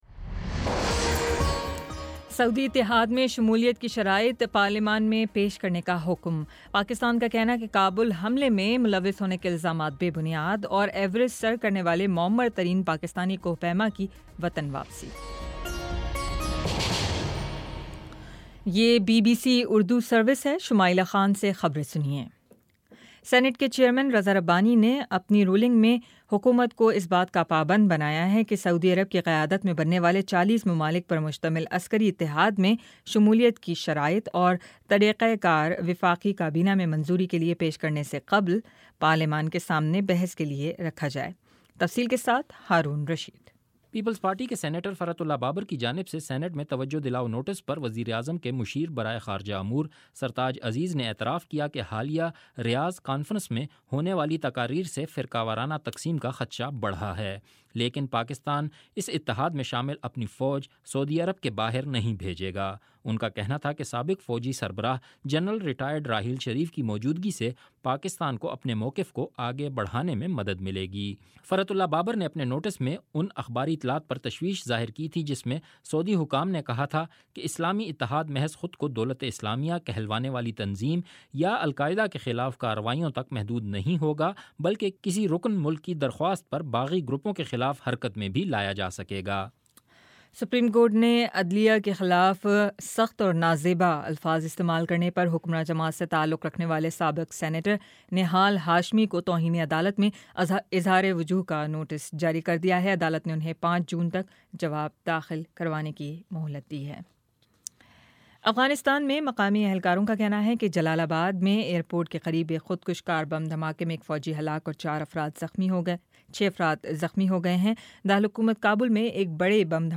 جون 01 : شام چھ بجے کا نیوز بُلیٹن